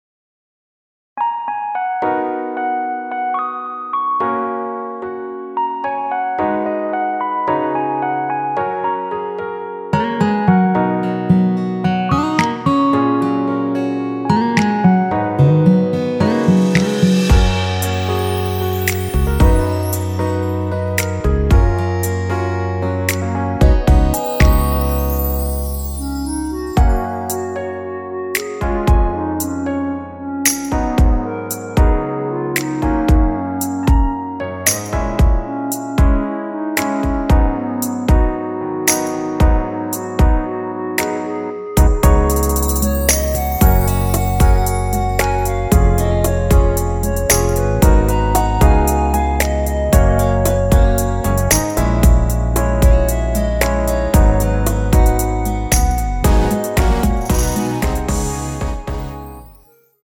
원키에서(-1)내린 멜로디 포함된 MR입니다.(미리듣기 확인)
F#
앞부분30초, 뒷부분30초씩 편집해서 올려 드리고 있습니다.